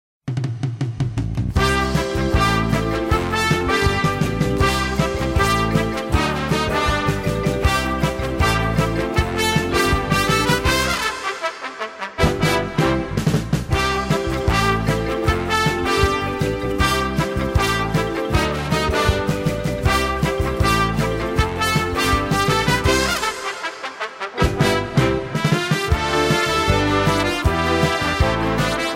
Besetzung: Blasorchester
Tonart: C-Dur auf Des-Dur.